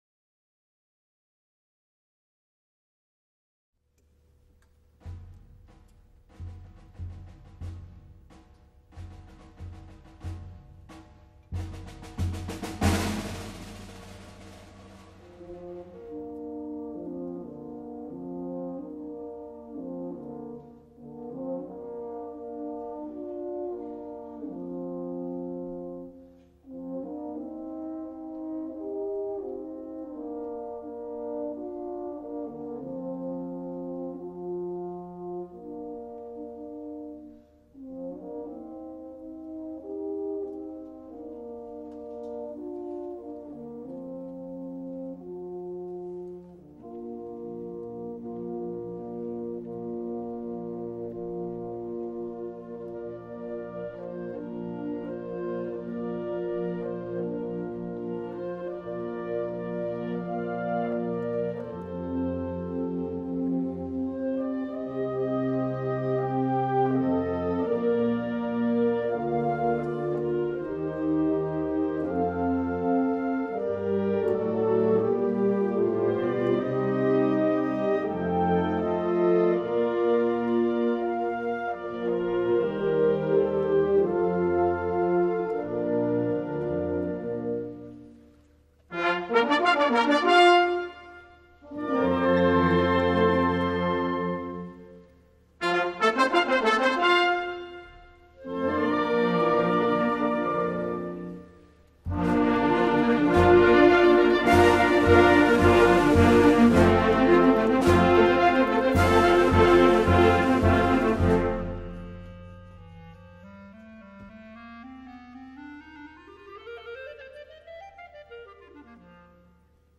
<<Un tamburo lontano sembra richiamare il passo dei soldati; due euphonium intonano, in un clima nostalgico, il tema della canzone popolare, che poi si estende alla coralità dei clarinetti; uno squillo (che ricorda quello dell’Inno di Garibaldi) incita i giovani ad unirsi per eroiche imprese; il volontario si allontana, ed il suo canto si perde in lontananza, mentre l’eco dell’Inno di Mameli richiama i valori della Patria per la quale ci si batte.>>: non avrei saputo analizzare e descrivere meglio questa rielaborazione della nota melodia del canto in questione.